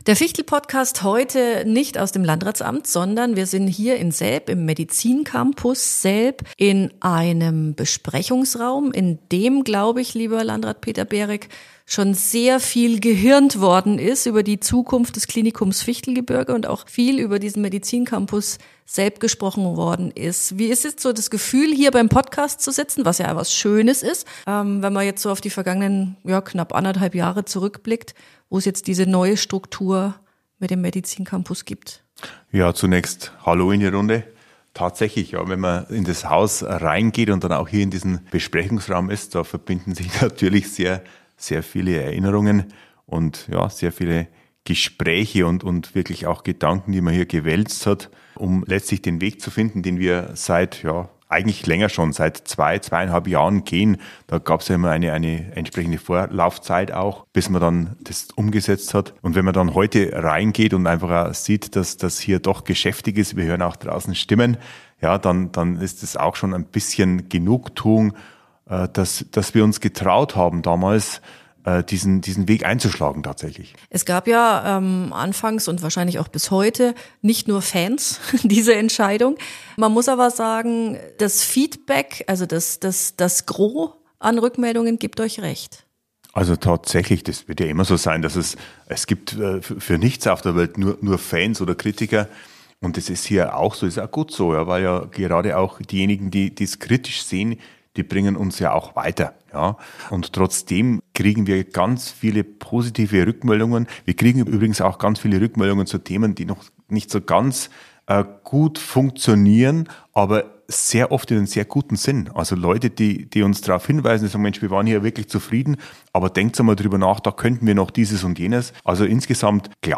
Im Podcast gibt Verwaltungsratsvorsitzender und Landrat Peter Berek Einblicke in den aktuellen Stand, spricht über bestehende Angebote und wagt den Blick nach vornWelche Perspektiven eröffnen sich für Patientinnen, Patienten und die Bevölkerung des Landkreises und darüber hinaus für die Zukunft?